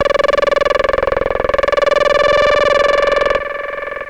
Loudest frequency 1009 Hz Recorded with monotron delay and monotron - analogue ribbon synthesizer